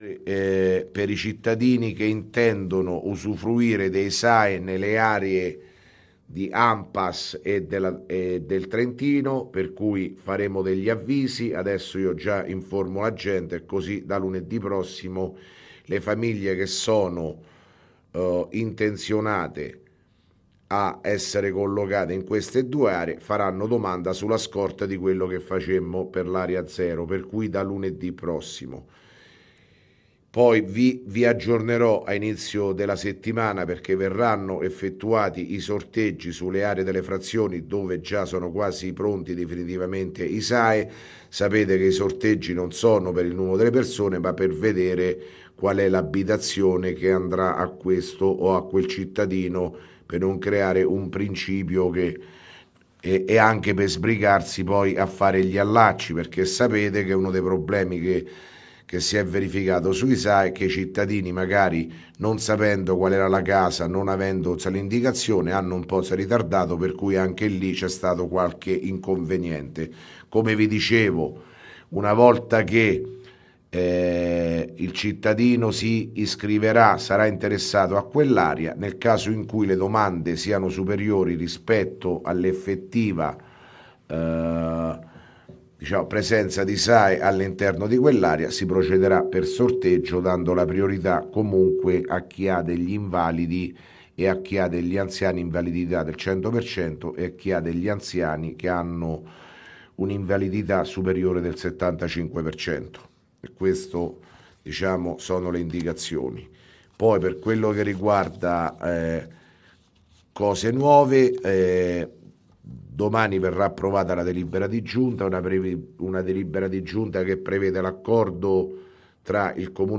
Di seguito il messaggio audio del Sindaco Sergio Pirozzi del 22 marzo 2017